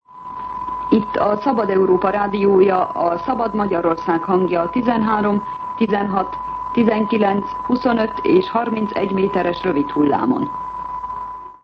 Frekvenciaismertetés